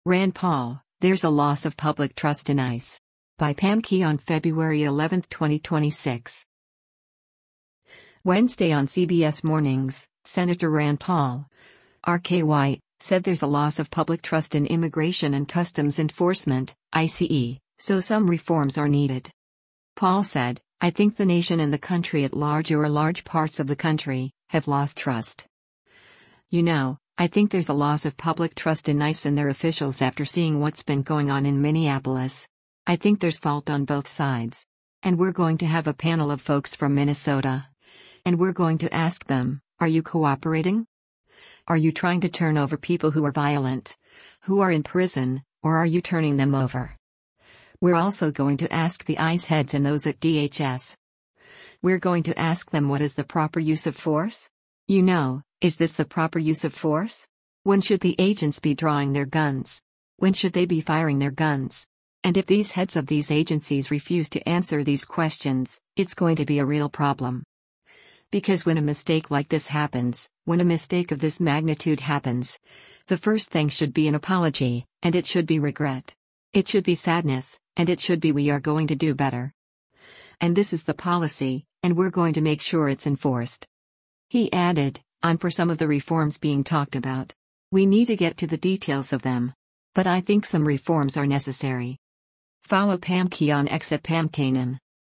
Wednesday on “CBS Mornings,” Sen. Rand Paul (R-KY) said “there’s a loss of public trust” in Immigration and Customs Enforcement (ICE) so some reforms are needed.